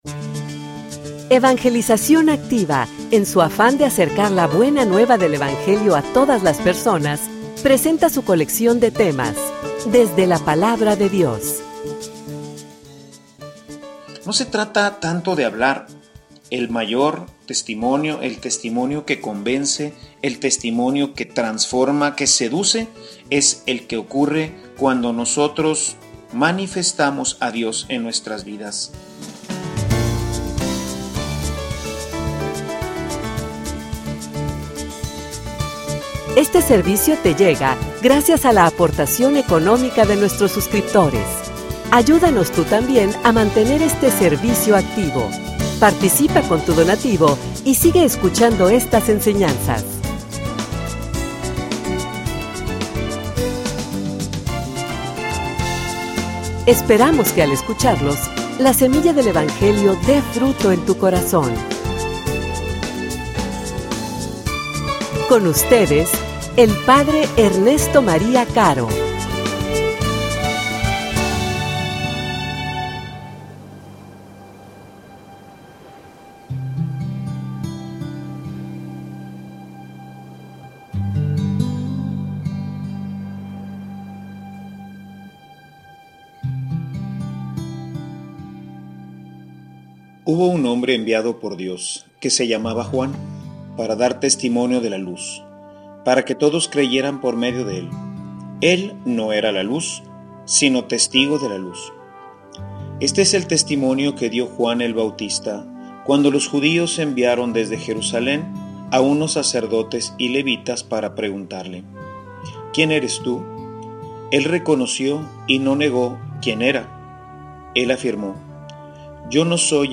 homilia_Cual_es_tu_testimonio.mp3